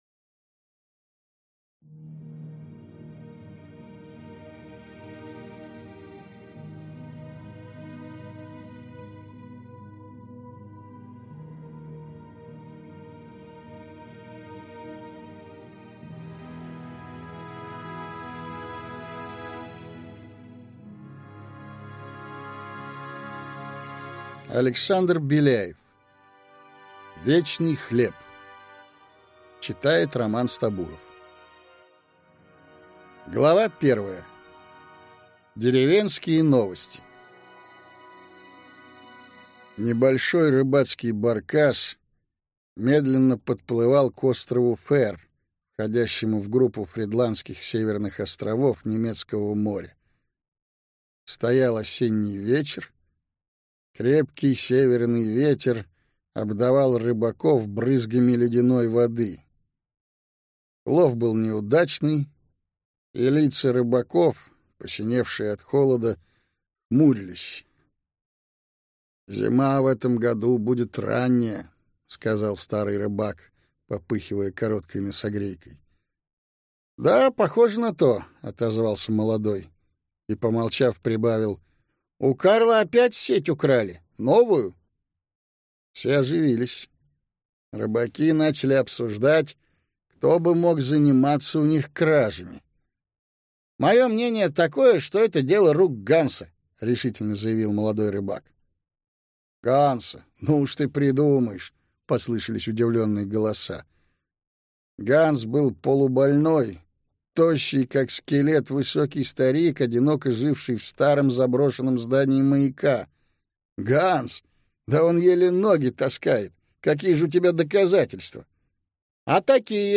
Фантастический роман Беляева в новой озвучке!Александр Беляев (1884–1942) был ребенком с необыкновенно широким кругом интересов.